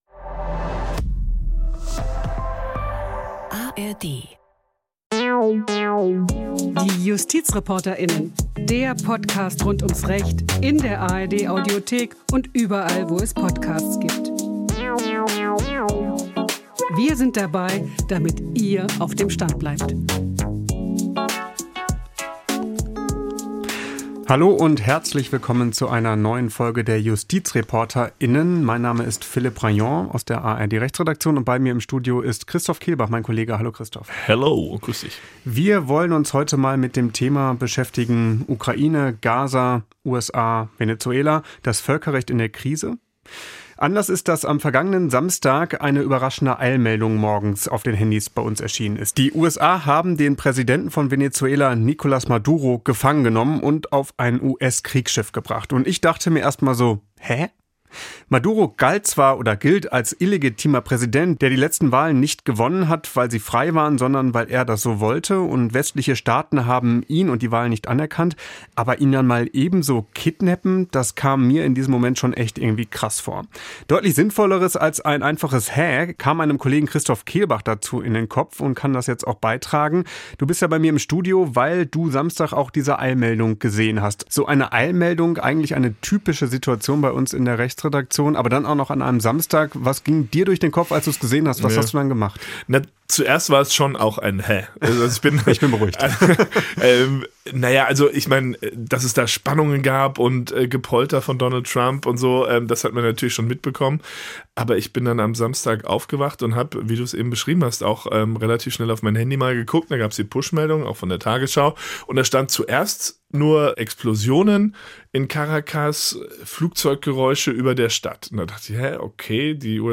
Außerdem interviewen sie den Professor für Völkerrecht